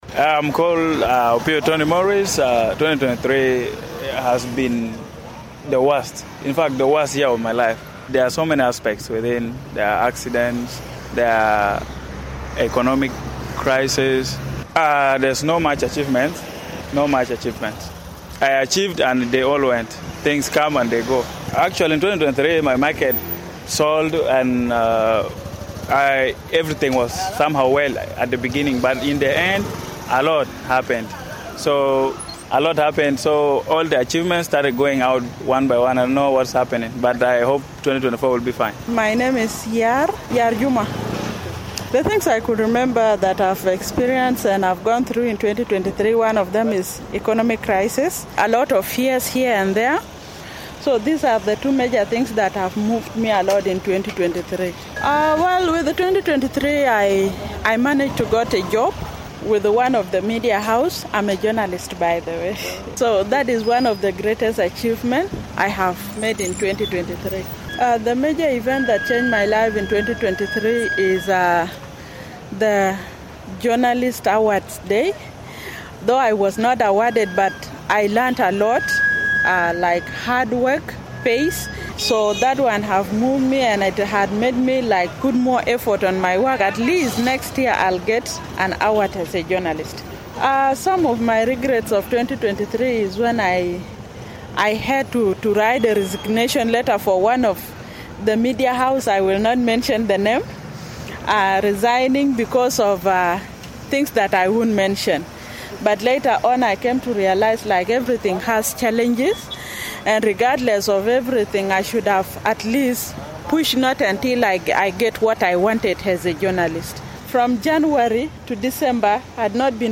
Reflections on 2023: South Sudanese Voices from the Streets of Juba [5:57]